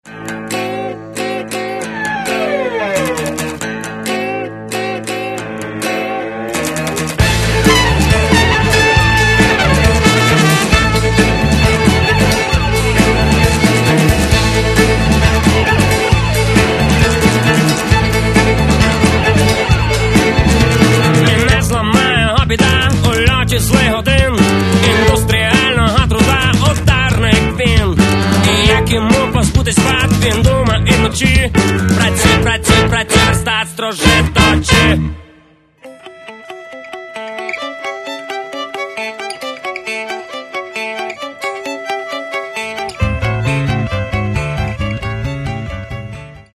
Каталог -> Рок та альтернатива -> Фолк рок